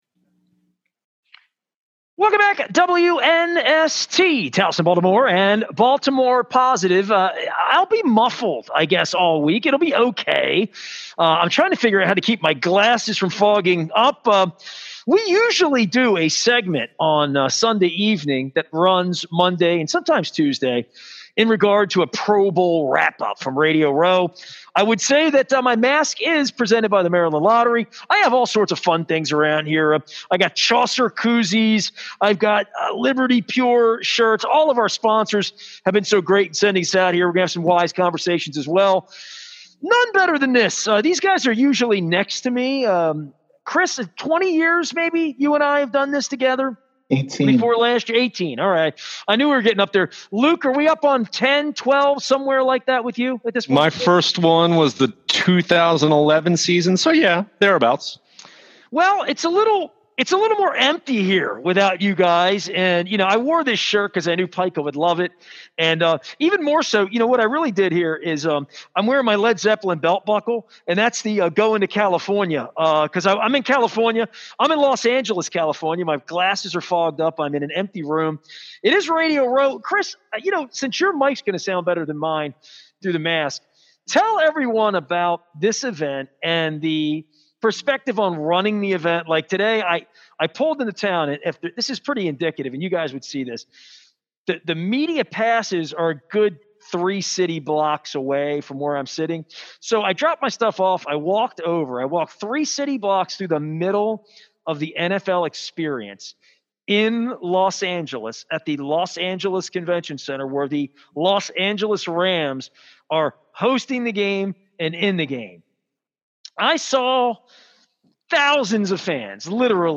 Radio Row in Los Angeles